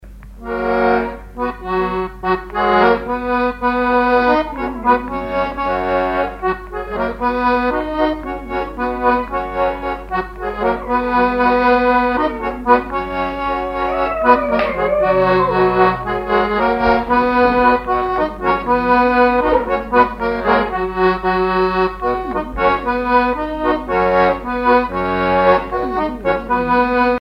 danse : marche
Répertoire sur accordéon diatonique
Pièce musicale inédite